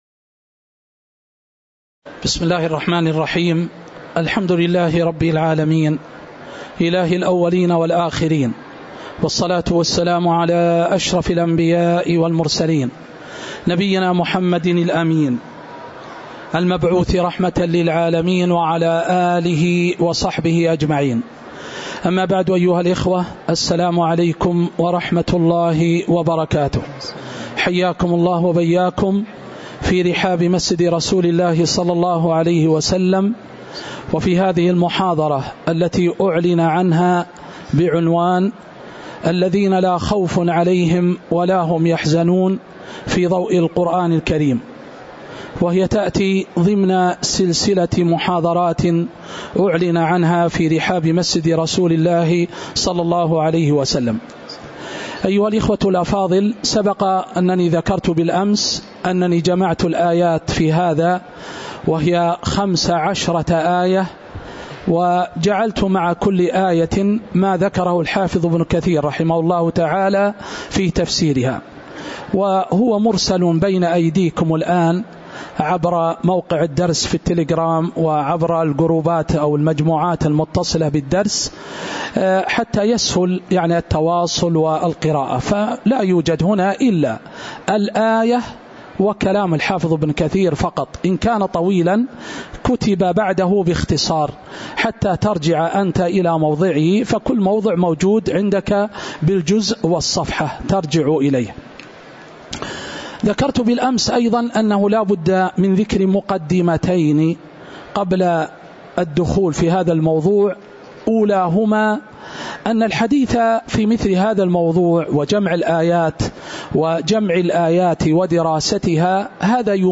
تاريخ النشر ٥ ربيع الأول ١٤٤٦ هـ المكان: المسجد النبوي الشيخ